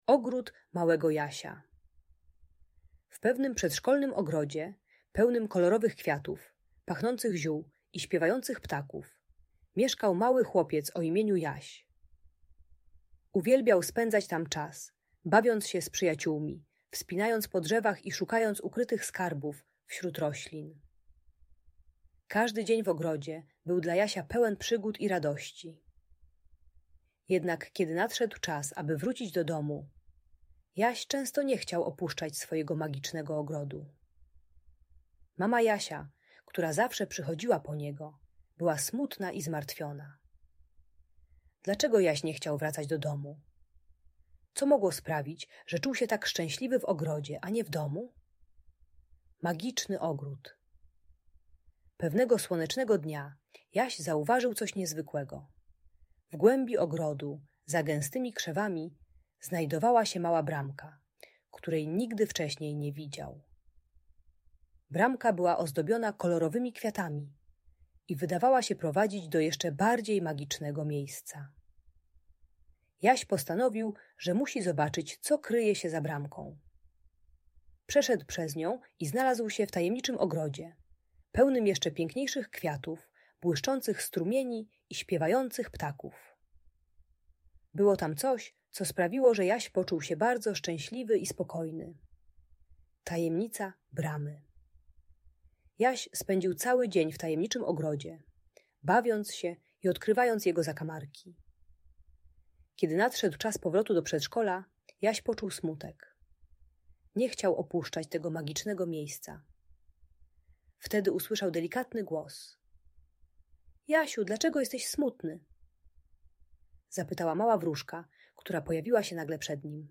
Opowieść o Ogrodzie Małego Jasia - Przywiązanie do matki | Audiobajka
Bajka dla dziecka które nie chce wracać do domu z przedszkola, przeznaczona dla dzieci 3-5 lat. Ta audiobajka o przywiązaniu do matki pomaga maluchowi zrozumieć, że dom może być równie magiczny jak przedszkole.